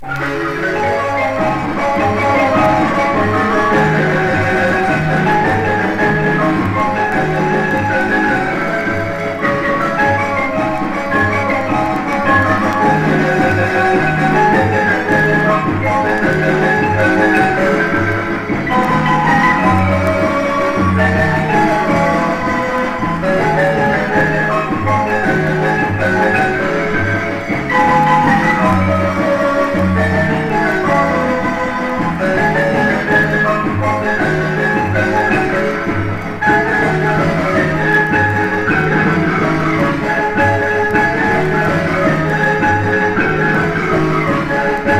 こちらは現地録音盤で、メキシコの観光都市オアハカのマリンババンドが演奏する様を収録。地元の演奏家らによる9人編成と3人編成バンドの音が録音としてはざらっとしていていながら、街の空気に響き溶けるような様が素晴らしい。打鍵の熱量、生み出されるポコポコとした音がとても心地良いです。
World, Field Recording　USA　12inchレコード　33rpm　Mono